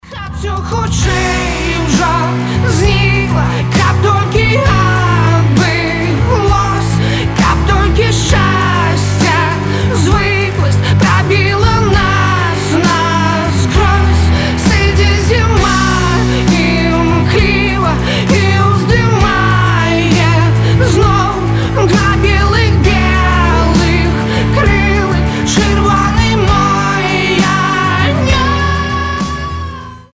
• Качество: 320, Stereo
красивые
грустные
Alternative Rock